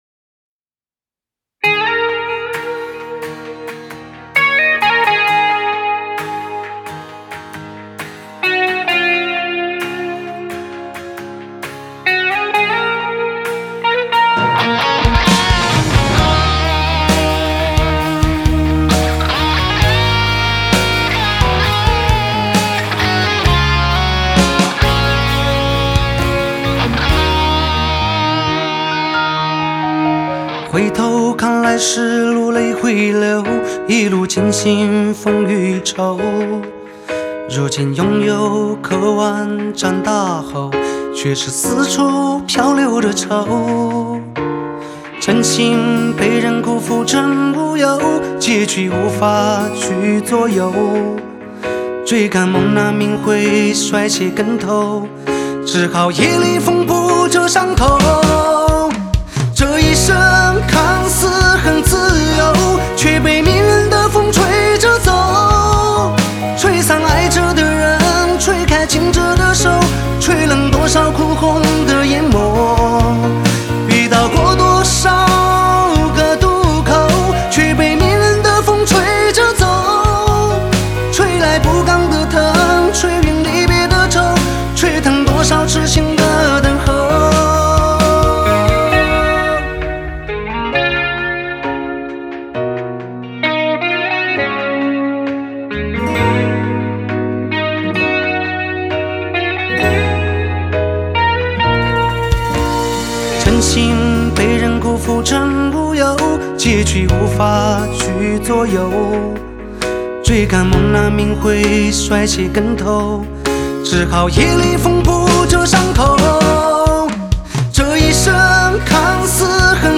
Backing Vocals
吉他 Guitarist